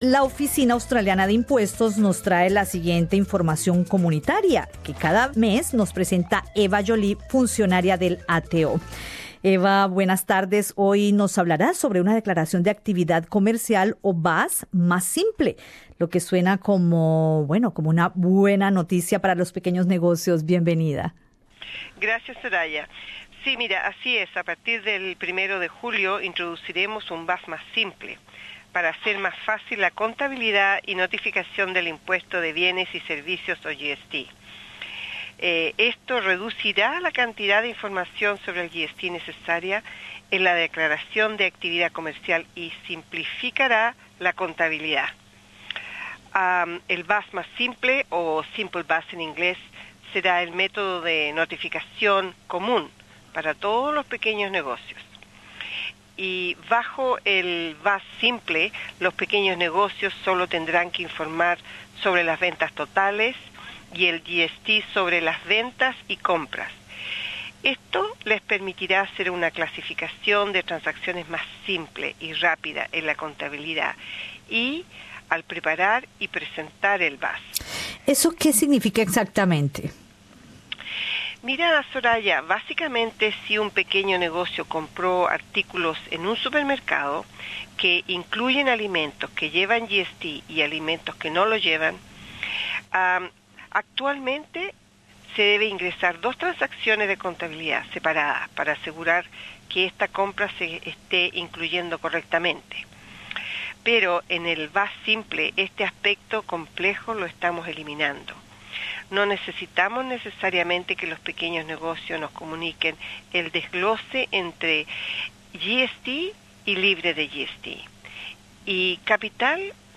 La siguiente información comunitaria es traida por la Oficina de Impuestos de Australia, ATO. Entrevista